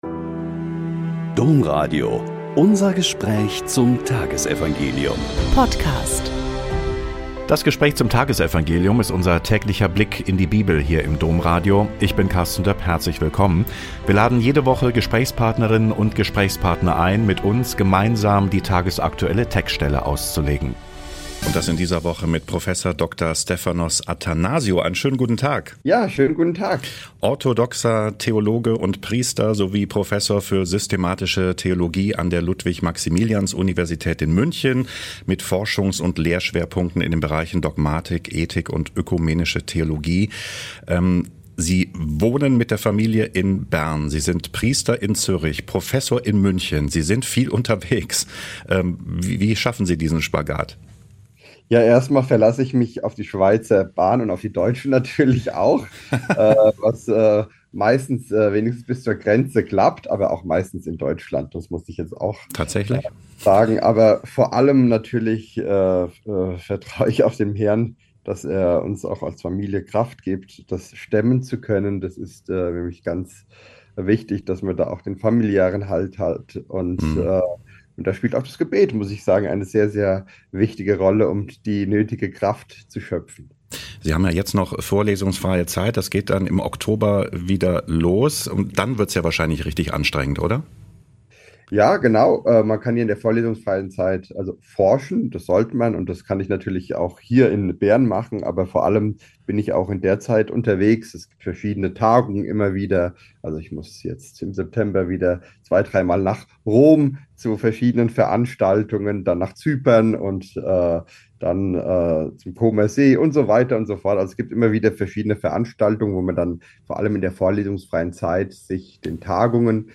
Mt 19,23-30 - Gespräch